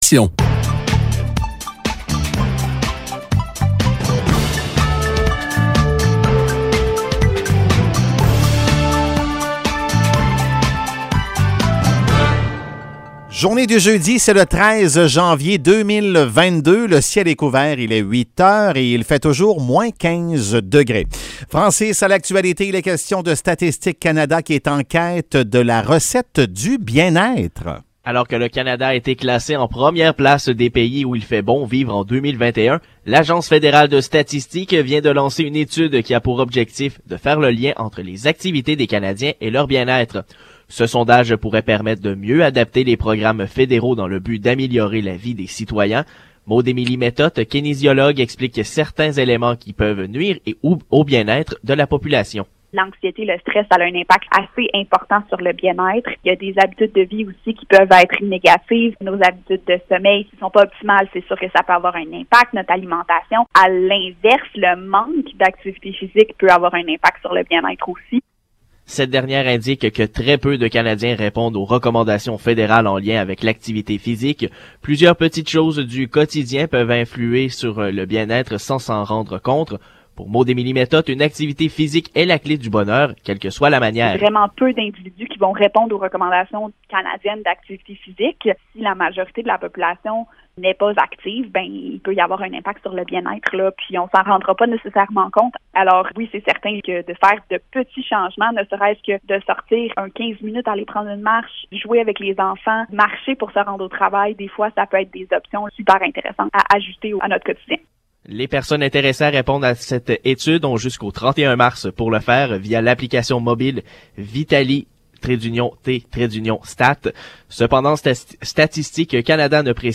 Nouvelles locales - 13 janvier 2022 - 8 h